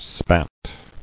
[spat]